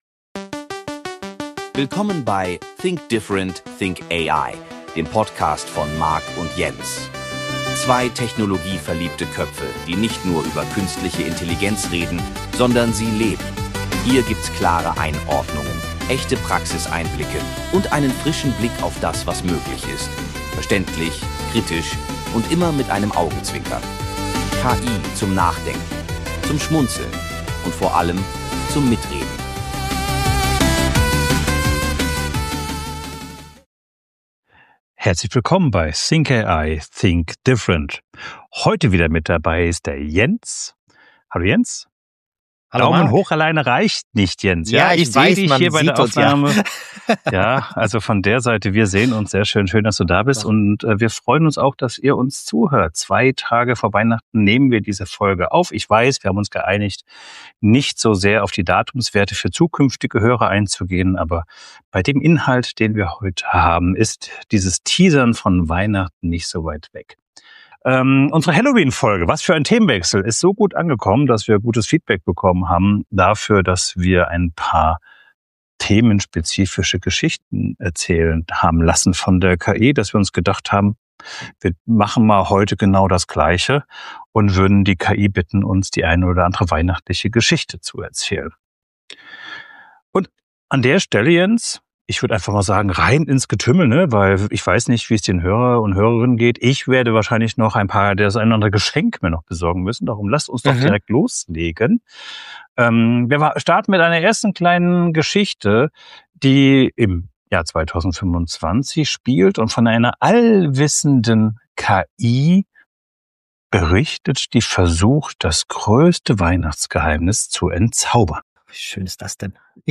Wir erzählen festliche KI-Geschichten, diskutieren über seelenlose Schlittenfahrten und zeigen, wie moderne Technik das Schenken verändern kann. Gemeinsam hinterfragen wir, ob Maschinen wirklich Magie verstehen – und wo Herz und Menschlichkeit unersetzlich bleiben. Unsere Gespräche entstehen wie immer spontan und authentisch, direkt aus dem Moment heraus.